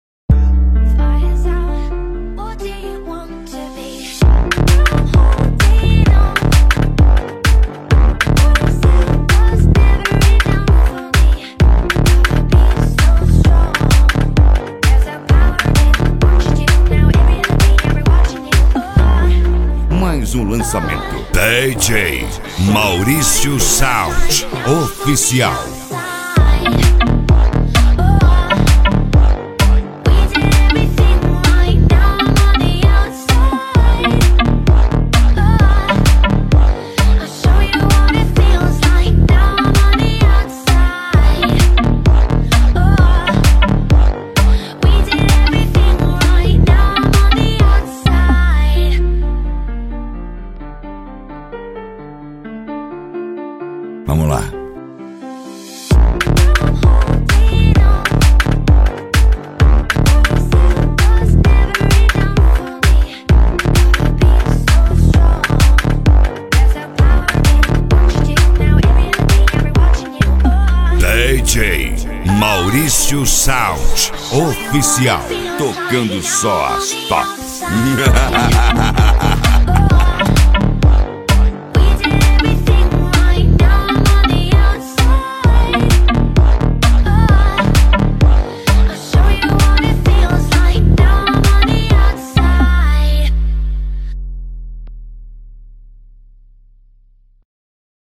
Eletro Funk